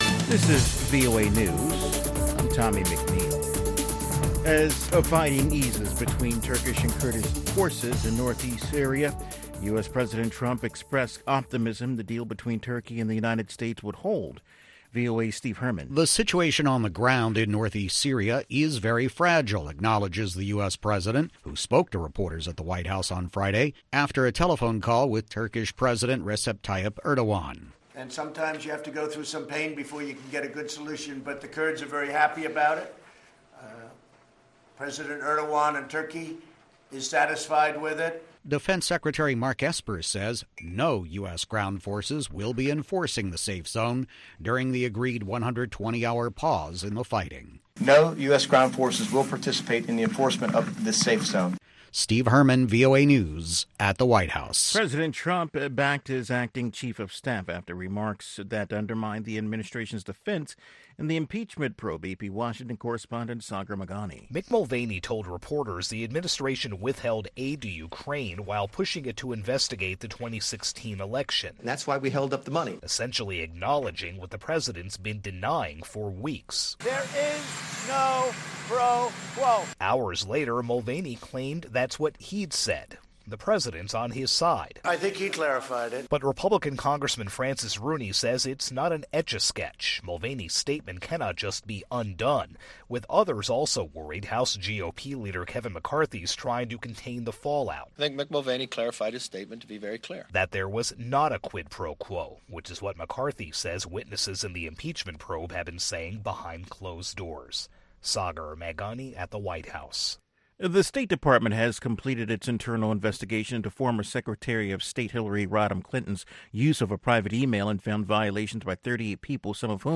Music Time in Africa is VOA’s longest running English language program. Since 1965, this award-winning program has featured pan African music that spans all genres and generations.